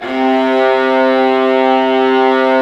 Index of /90_sSampleCDs/Roland LCDP13 String Sections/STR_Violas II/STR_Vas4 Amb f